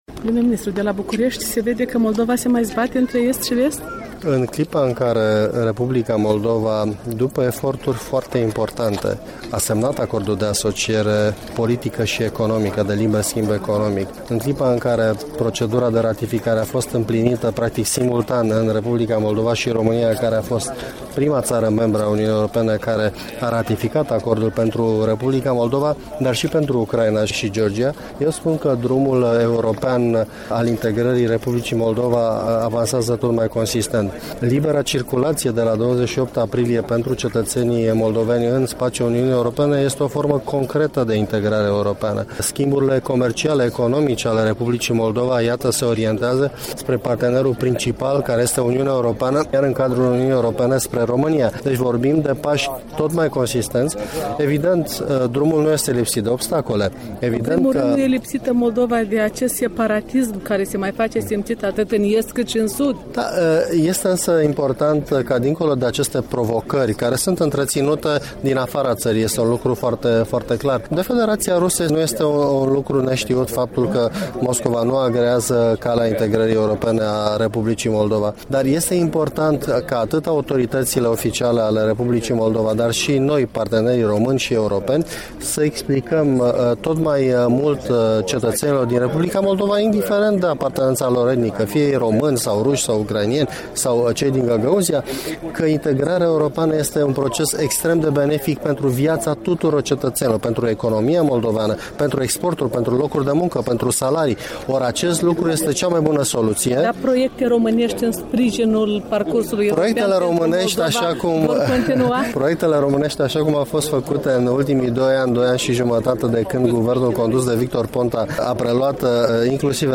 Interviu cu ministrul român de externe Titus Corlăţean